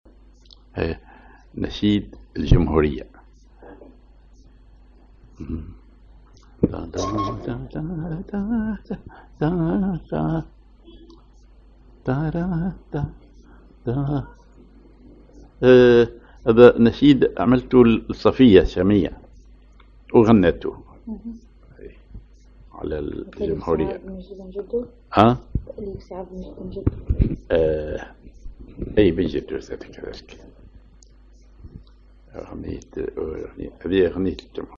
Maqam ar عجم على الراست
genre نشيد